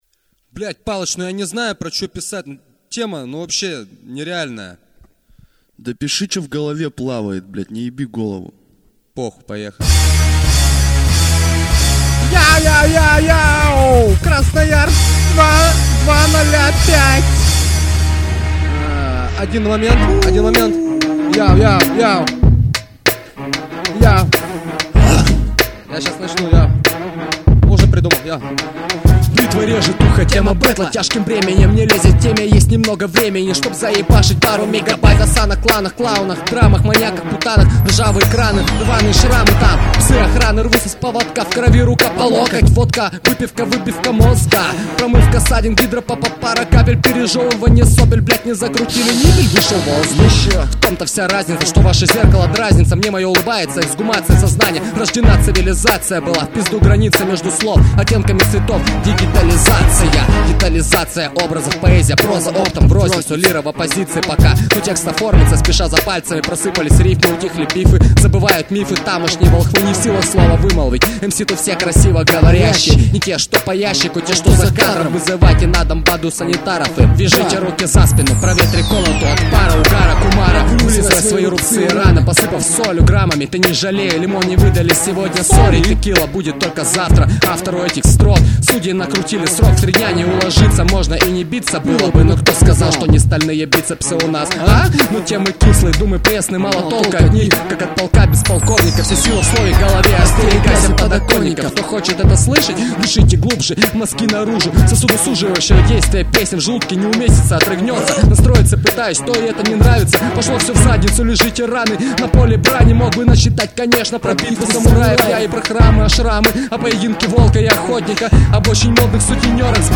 • Жанр: Рэп
баттл RapWar